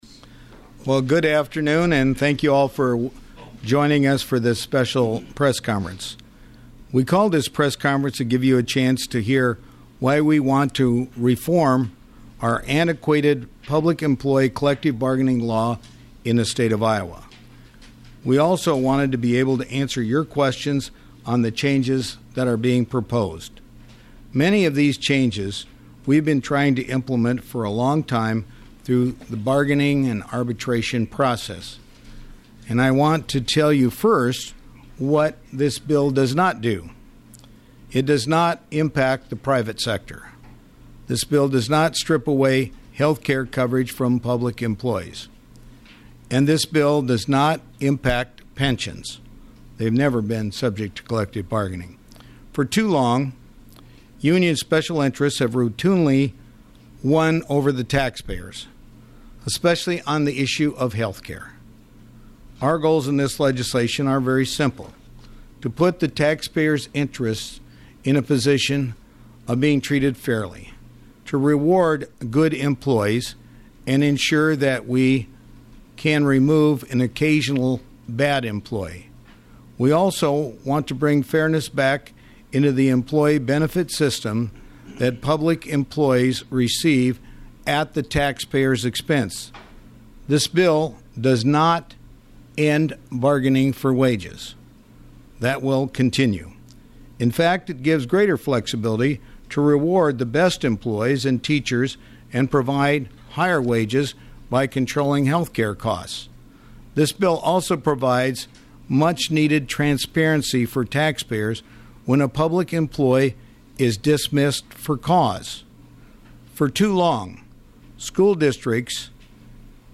In a rare move, Reynolds and Branstad held an afternoon news conference with the Republican leaders in the House and Senate to discuss the legislation. AUDIO of news conference in Branstad’s office, 23:00 House Speaker Linda Upmeyer, a Republican from Clear Lake, said this bill should be “no surprise” since House Republicans tried to make many of these changes six years ago.